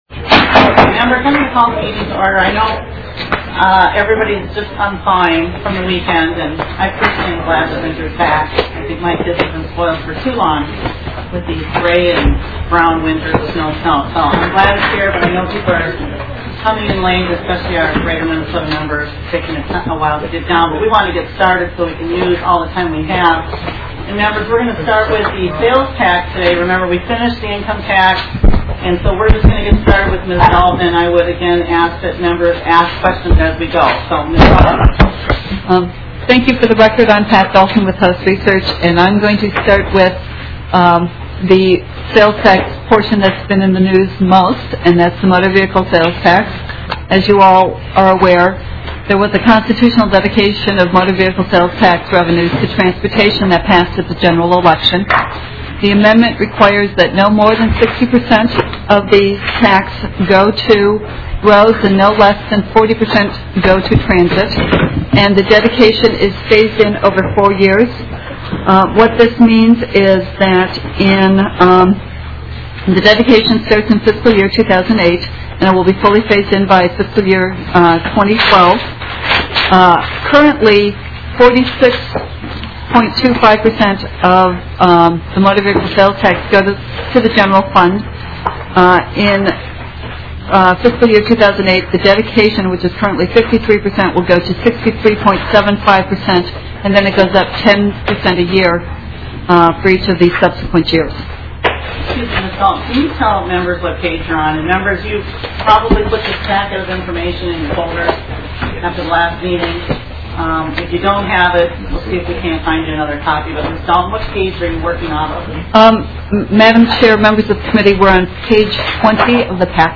Taxes THIRD MEETING 2007-2008 Regular Session - Tuesday, January 16, 2007 STATE OF MINNESOTA THIRD MEETING HOUSE OF REPRESENTATIVES EIGHTY-FIFTH SESSION TAX COMMITTEE MINUTES Representative Ann Lenczewski, Chair of the Committee, called the meeting to order at 10:03 A.M. on Tuesday, January 16, 2007, in Room 10 of the State Office Building.
Audio: Listen Now Show Full Schedule Agenda: House briefing continued.